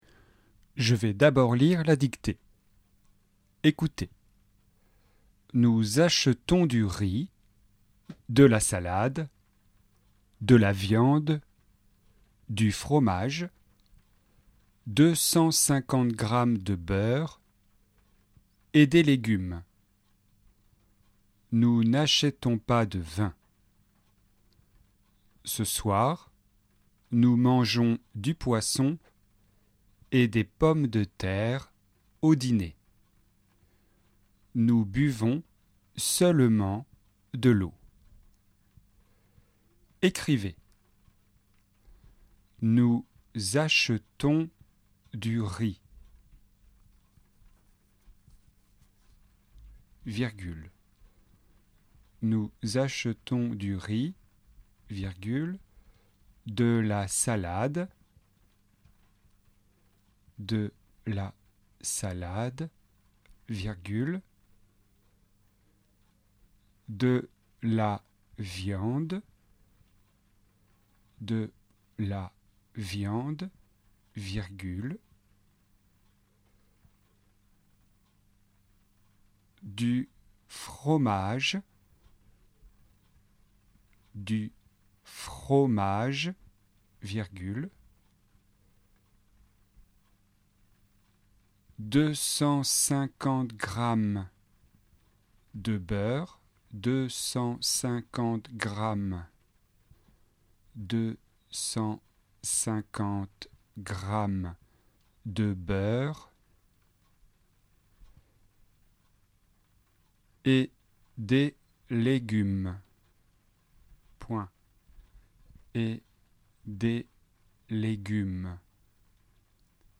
Dictée 11 : la nourriture ( article partitif)